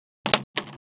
Index of /traerlab/AnalogousNonSpeech/assets/stimuli_demos/jittered_impacts/small_rubber_longthin_yellowrubbertubing